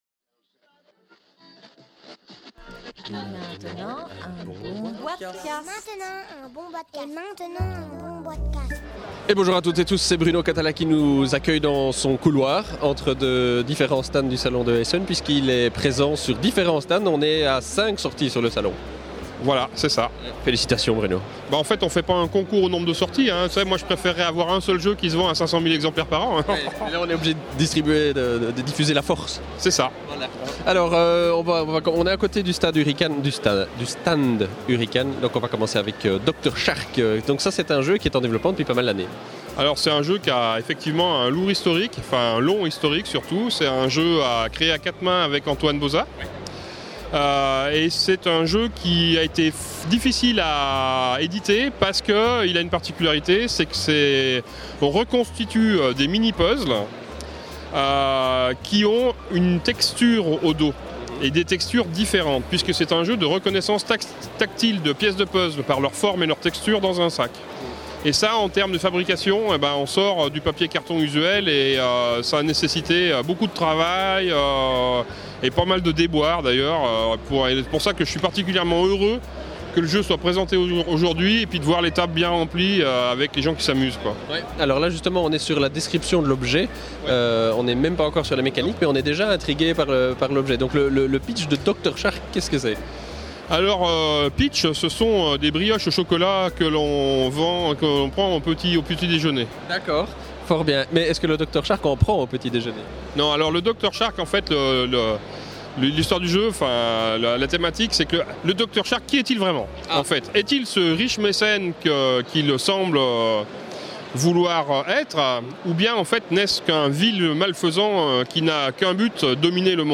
(enregistré au Salon international du Jeu de Société de Essen – Octobre 2011)